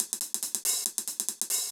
Index of /musicradar/ultimate-hihat-samples/140bpm
UHH_AcoustiHatA_140-04.wav